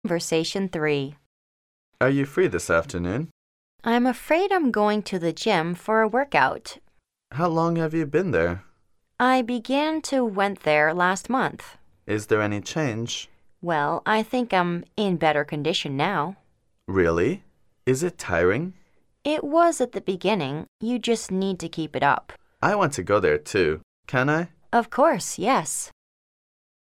Conversation 3